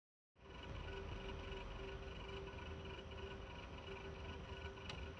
Pumpe.mp3
Klingt nach kaputter Pumpe.